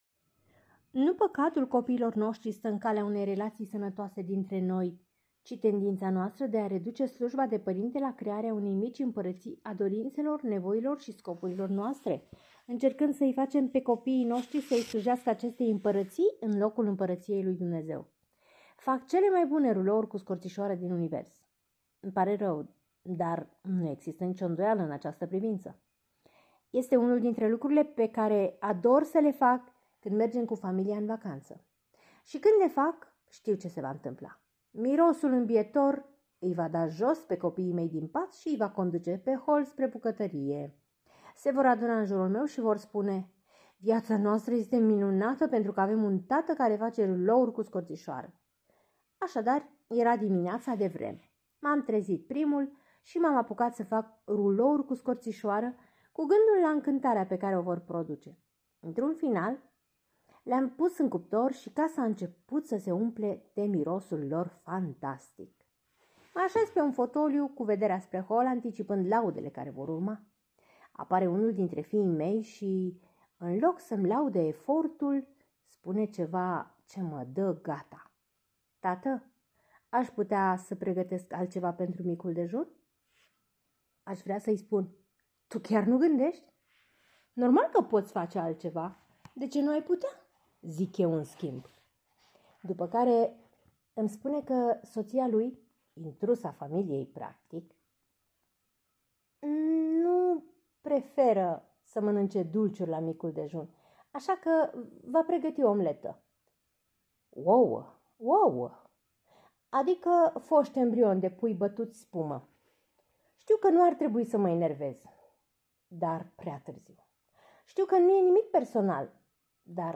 Capitolul este citit